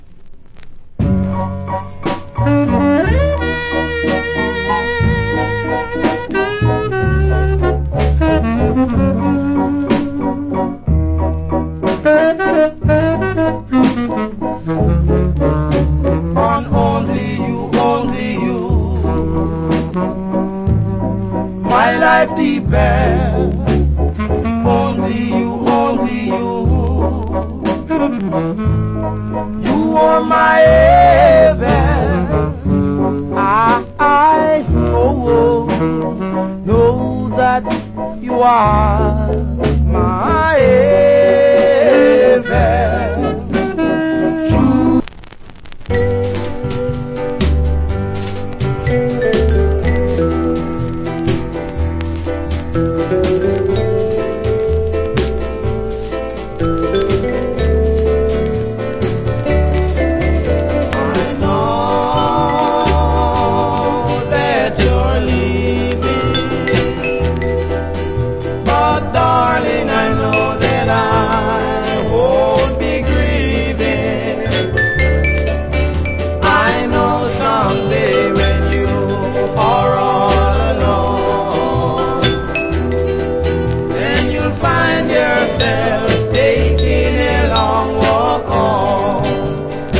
Greag Duet Jamaican R&B Vocal.